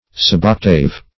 Search Result for " suboctave" : The Collaborative International Dictionary of English v.0.48: Suboctave \Sub*oc"tave\, Suboctuple \Sub*oc"tu*ple\, a. Containing one part of eight; having the ratio of one to eight.